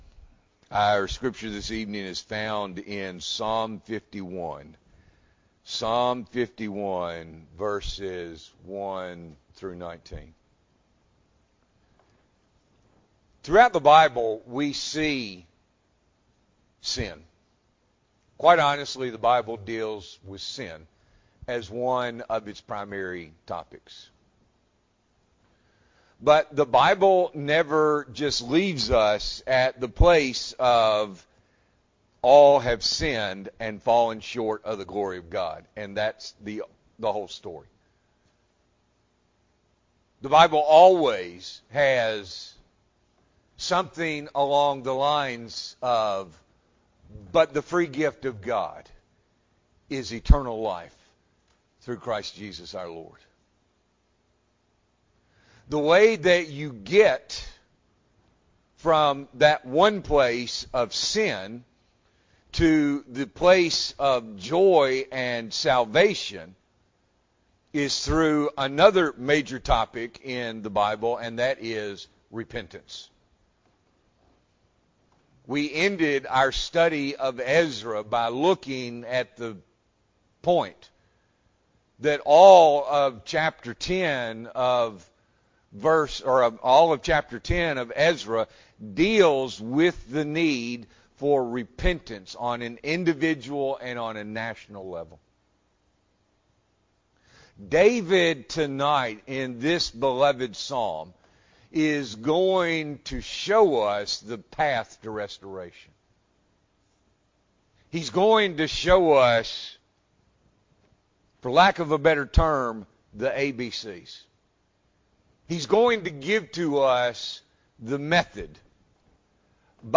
October 15, 2023 – Evening Worship